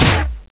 FIST.WAV